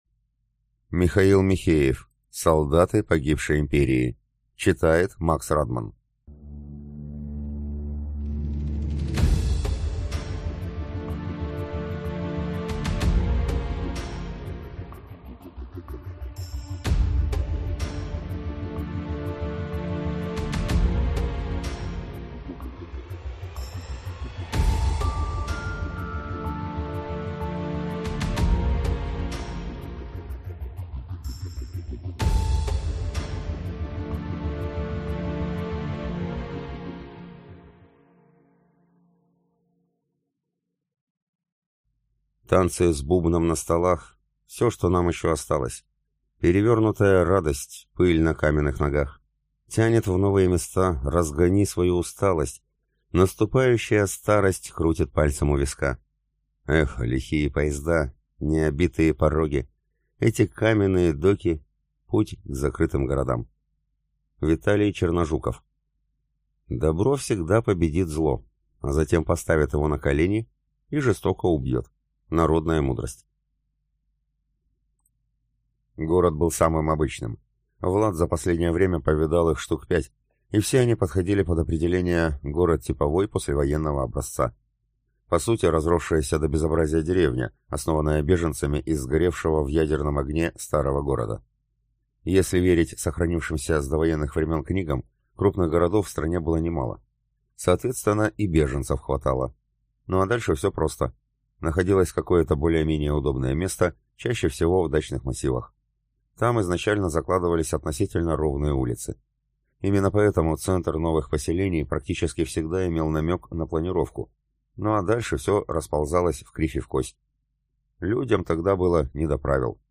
Аудиокнига Солдаты погибшей империи | Библиотека аудиокниг